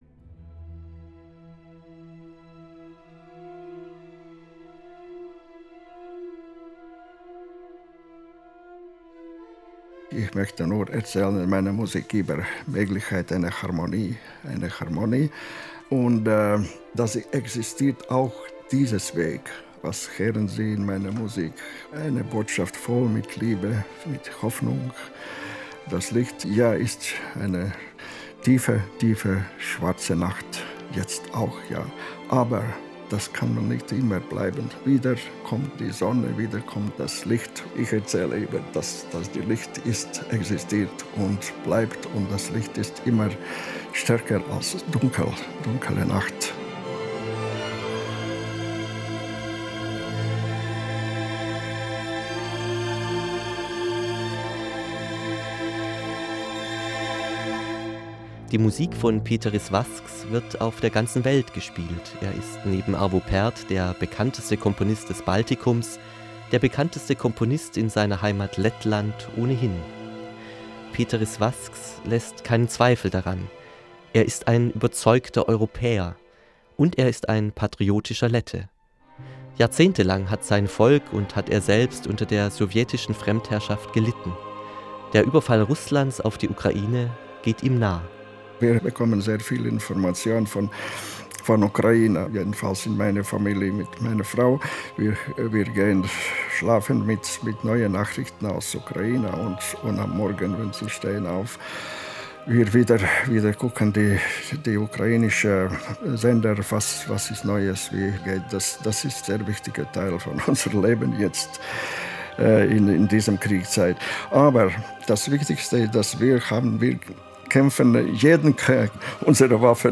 Porträt